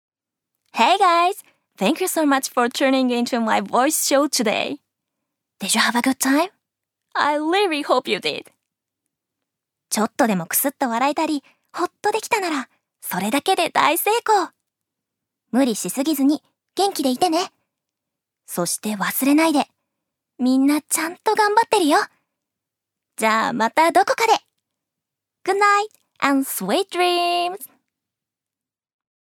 預かり：女性
ナレーション３